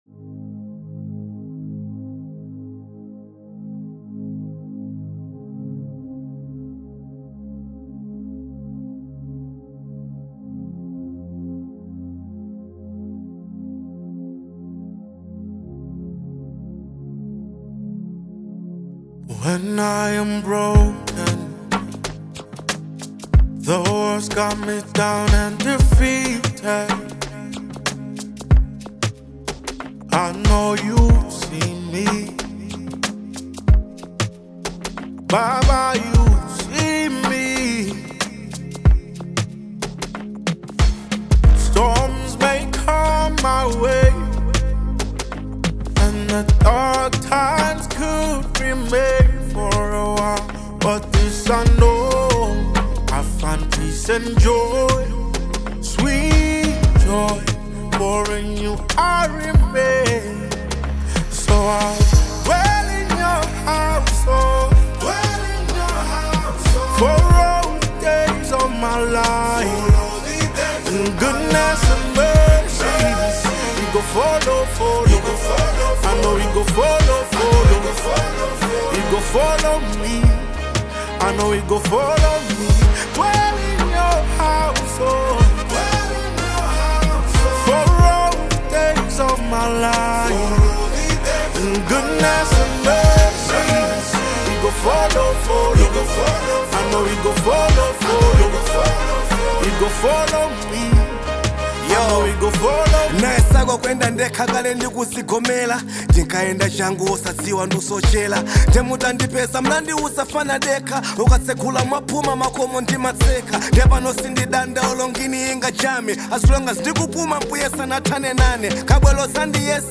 Genre : Gospel
The song opens with a melodic groove
soulful vocals
smooth harmonies that bring both reflection and energy